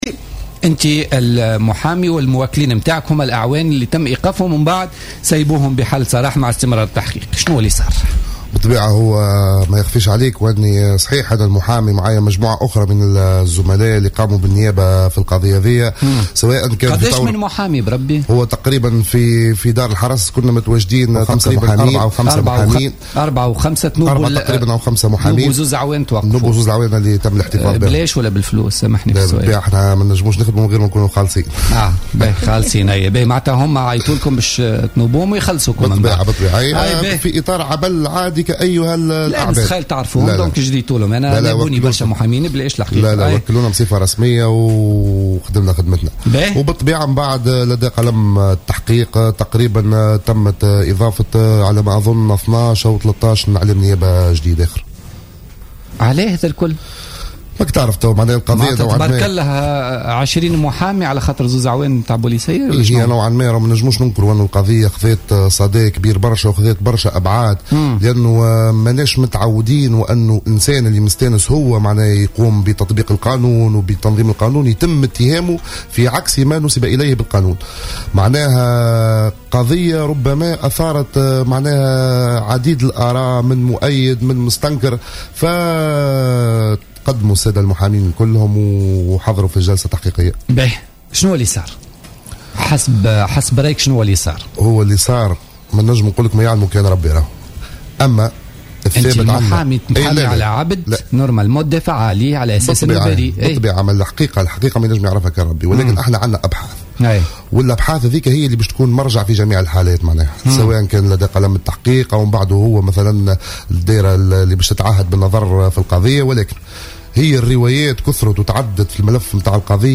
ضيف بوليتيكا اليوم الخميس